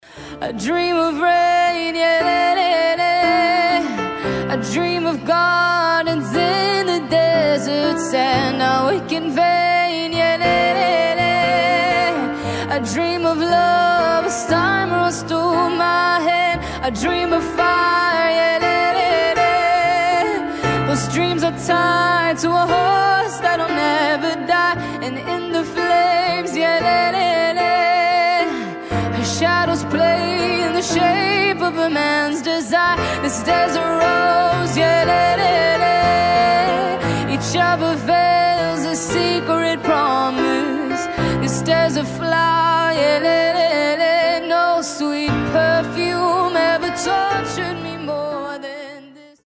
• Качество: 320, Stereo
Cover
пианино
красивый женский голос
live
красивый вокал
арабские
Живое исполнение, невозможно остановиться слушать